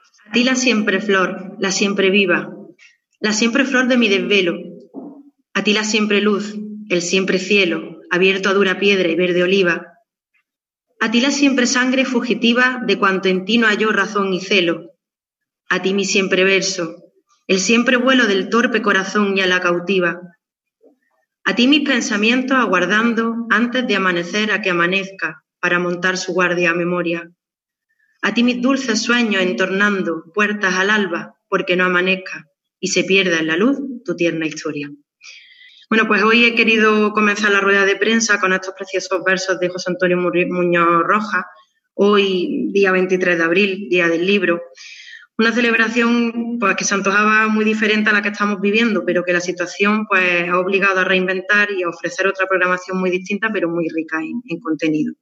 Unos versos del celebérrimo poeta antequerano José Antonio Muñoz Rojas iniciaban en la mañana de hoy jueves 23 de abril, Día del Libro, la rueda de prensa que tanto el alcalde de Antequera, Manolo Barón, como la teniente de alcalde Elena Melero han protagonizado para ofrecer un balance de actividad del Área de Cultura, Ferias, Tradiciones y Juventud durante el periodo de Estado de Alarma que aún sigue vigente.
Cortes de voz